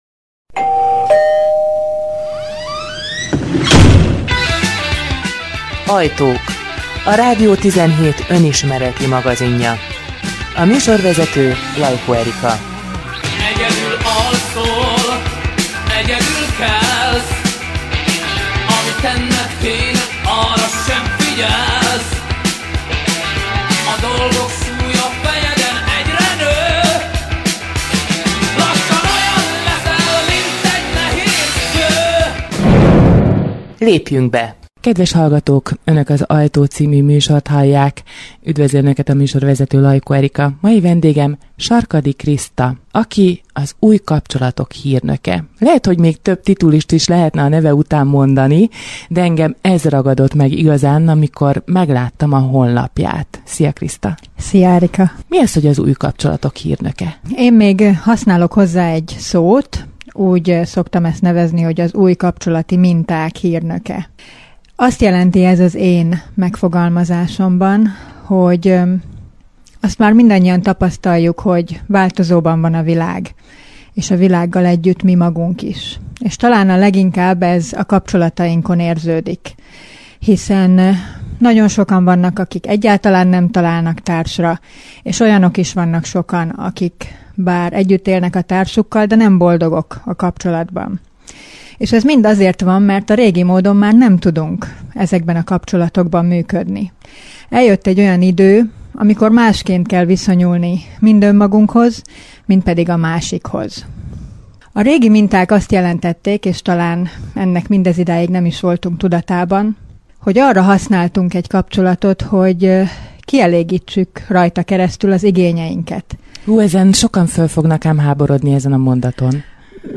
radio17_interju.mp3